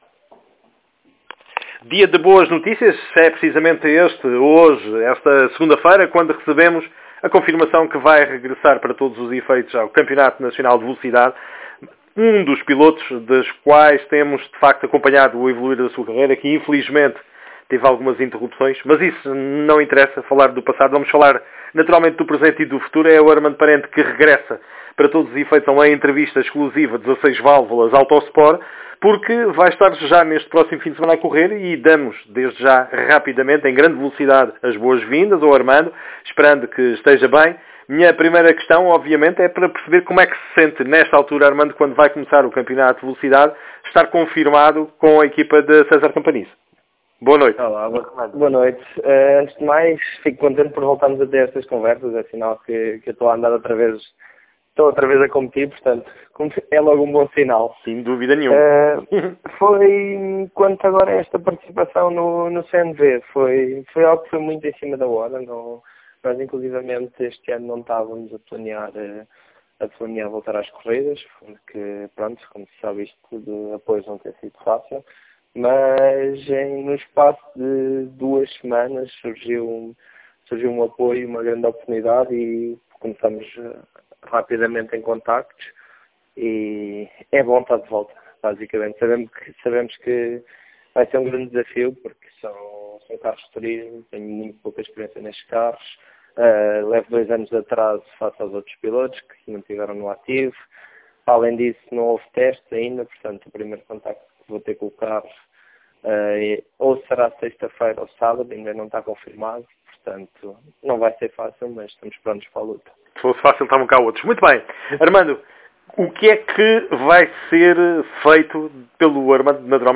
Entrevista áudio